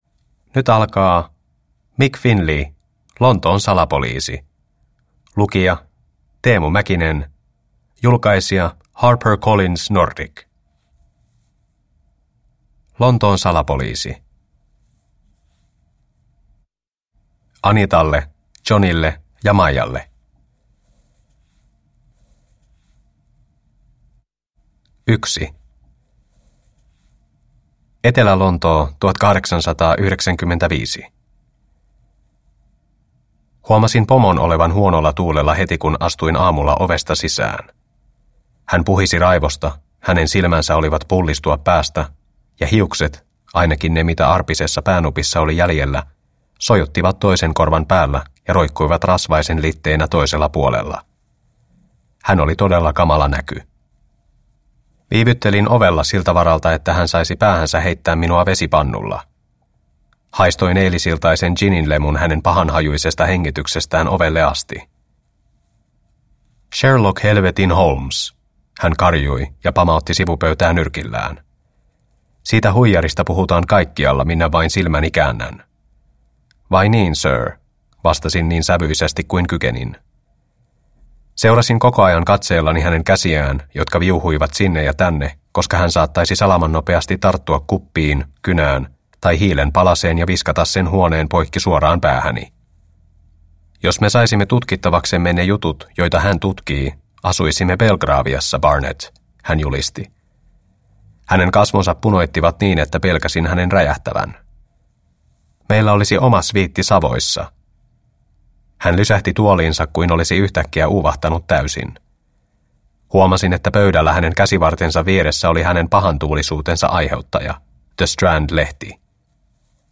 Lontoon salapoliisi – Ljudbok – Laddas ner